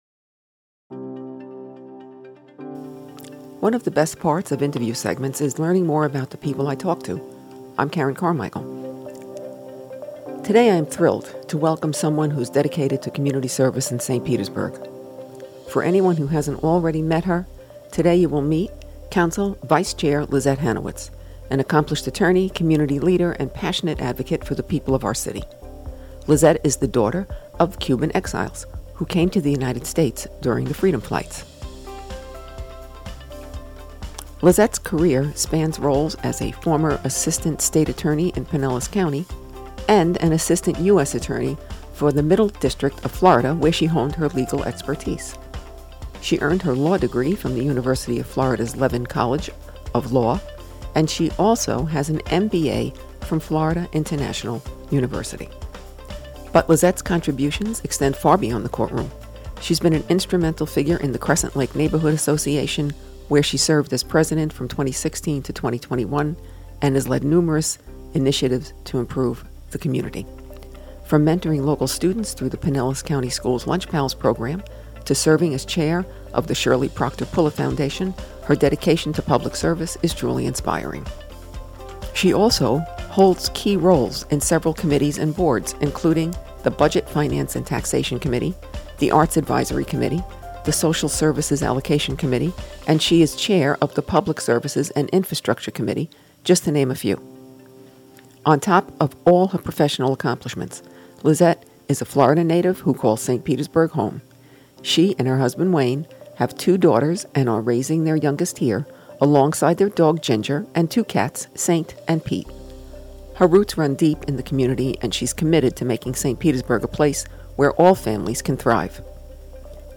St Petersburg City Council Vice Chair Lisset Hanewicz stopped in the studio to chat about her multifaceted career and personal journey. Lisset reflected on her legal career as a US attorney and state prosecutor, where she handled high-profile and complex cases.